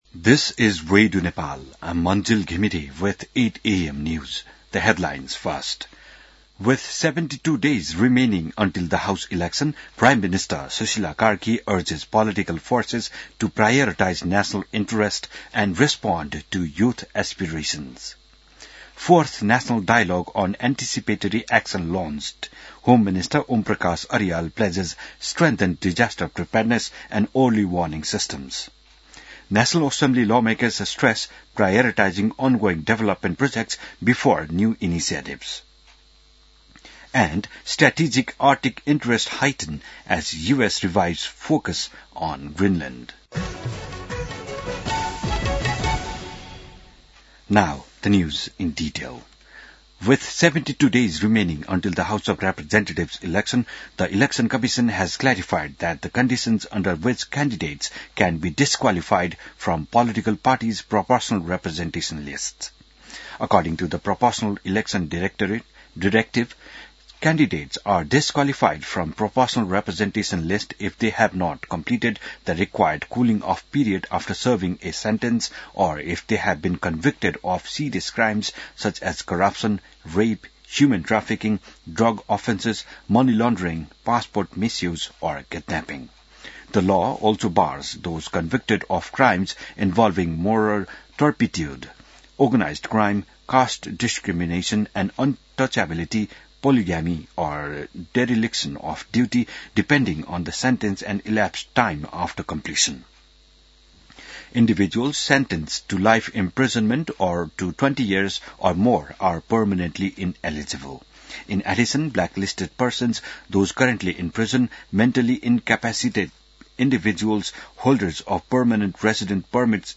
बिहान ८ बजेको अङ्ग्रेजी समाचार : ८ पुष , २०८२